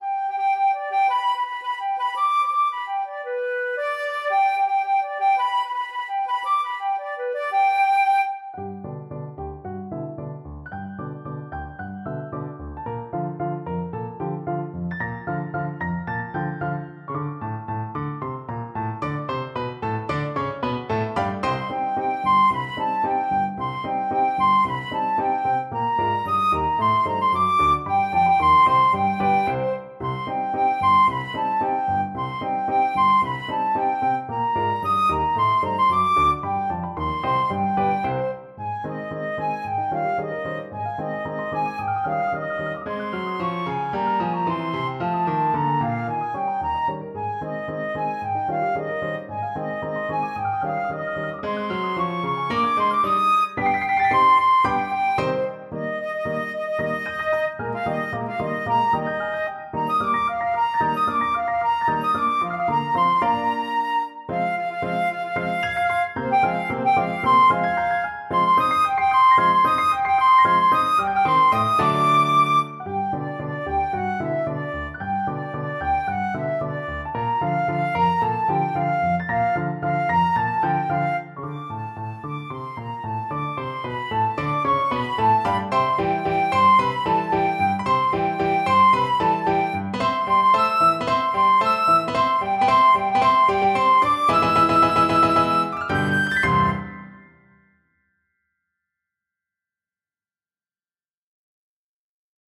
Classical Bizet, Georges Children's Chorus from Carmen Flute version
Flute
6/8 (View more 6/8 Music)
C minor (Sounding Pitch) (View more C minor Music for Flute )
Allegro .=112 (View more music marked Allegro)
Classical (View more Classical Flute Music)
carmen_chorus_of_children_FL.mp3